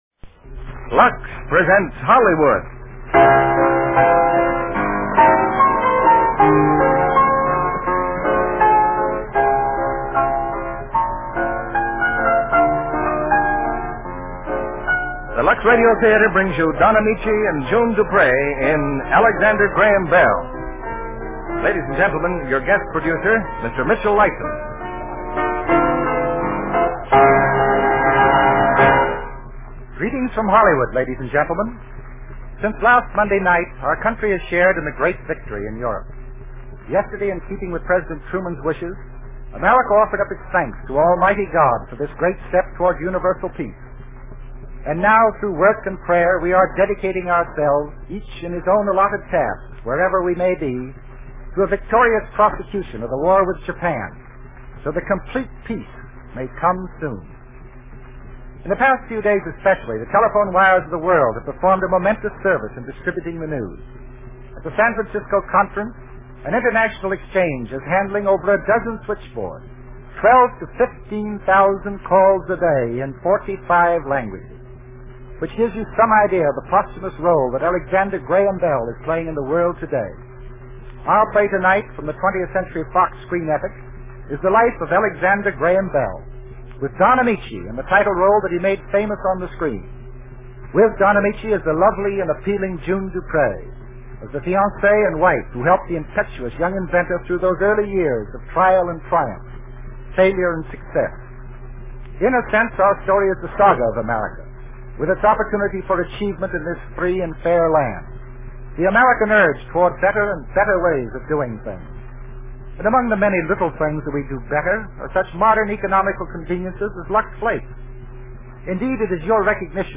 Lux Radio Theater Radio Show
Alexander Graham Bell, starring Don Ameche, June Duprez